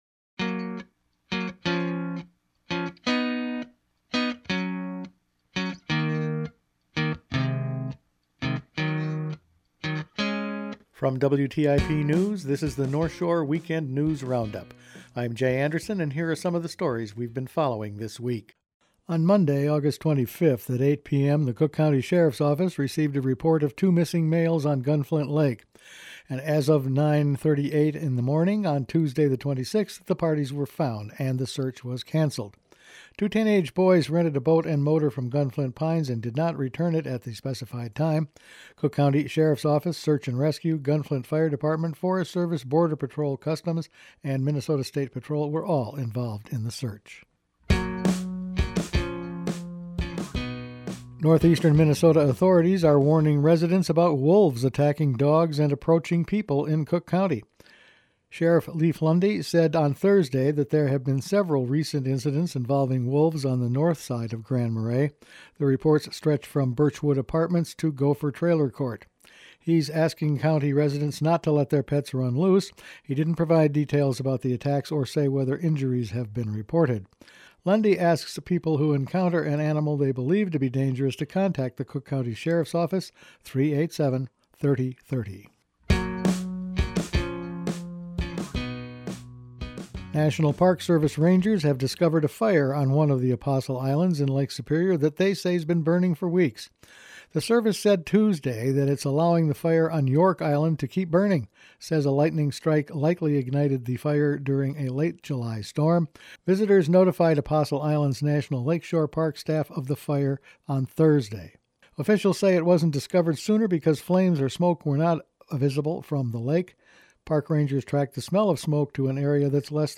Weekend News Roundup for August 30
Each week the WTIP news department puts together a roundup of the weeks top news stories. Missing boaters found, a wolf advisory made for pet owners, more money for the Great Lakes…all this and more in this week’s news.